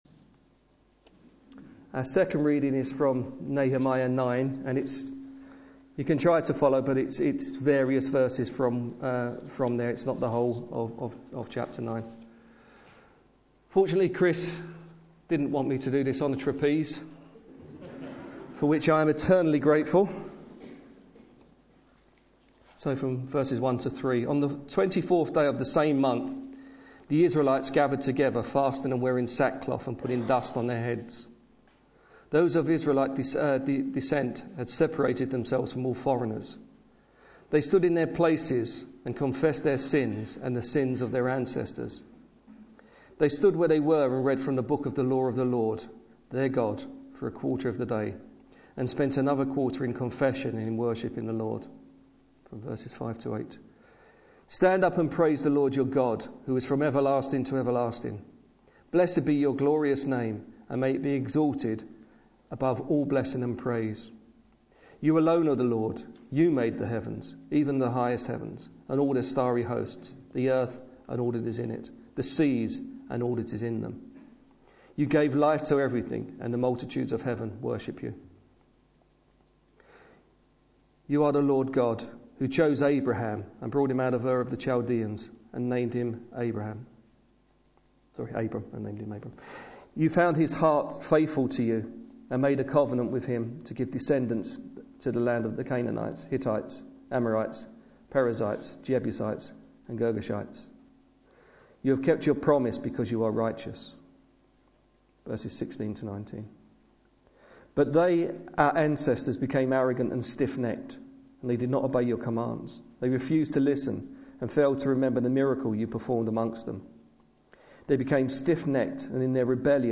Sunday Service
Our God, his word and his people Sermon